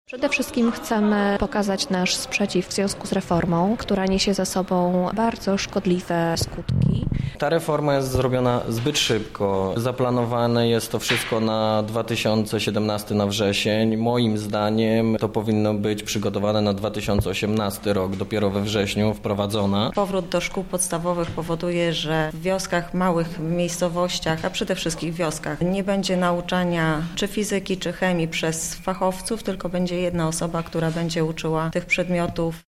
Jakie jeszcze zarzuty mają strajkujący pytał nasz reporter